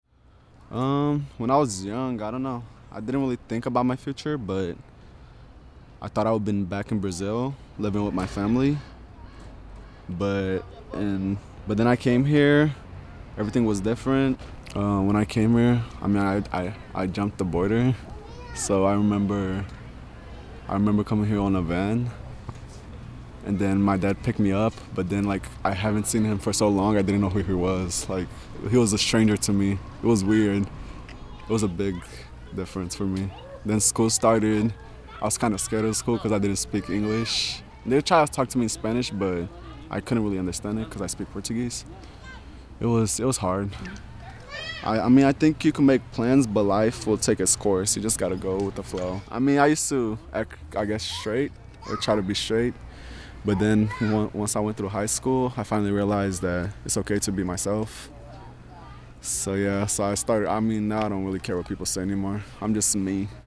I randomly approached him in Dolores Park in San Francisco and asked to record him speaking about what he's learned about himself so far in life.
This piece explores his journey through the confines of cultural, social, and political borders. Emotions in this story seems to include pensiveness, fear, confusion, self acceptance, pride, and courage.